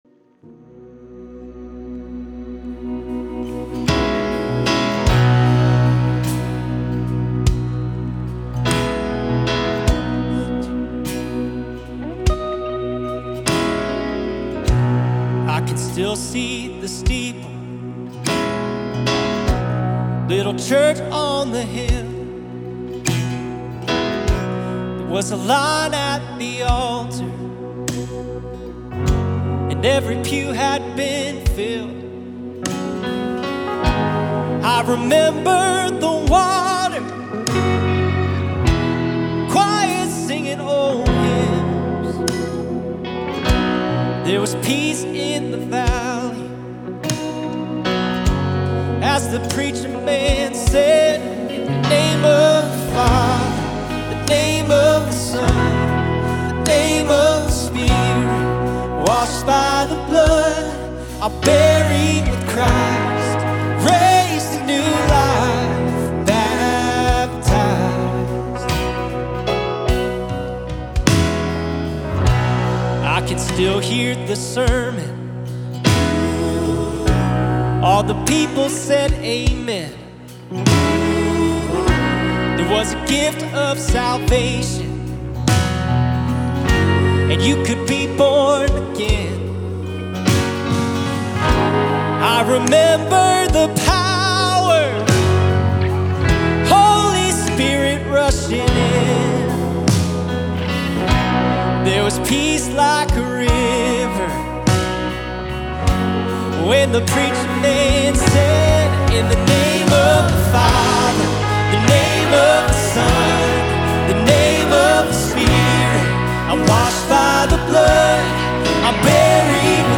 Baptism Service